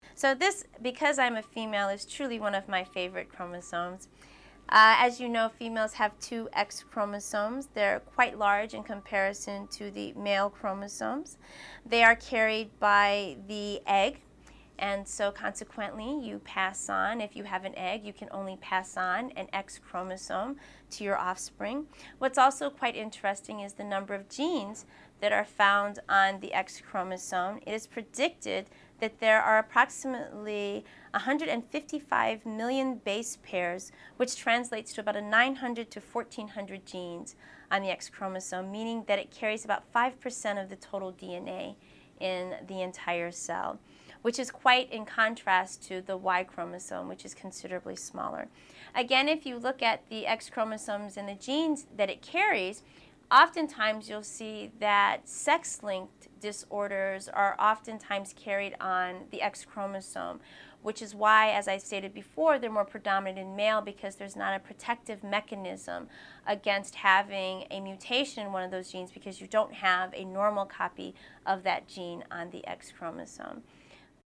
21.1 Talking Glossary: X-chromosome (1.25 min)